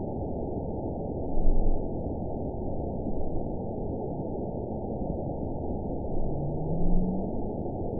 event 916935 date 02/20/23 time 03:21:46 GMT (2 years, 3 months ago) score 7.29 location TSS-AB10 detected by nrw target species NRW annotations +NRW Spectrogram: Frequency (kHz) vs. Time (s) audio not available .wav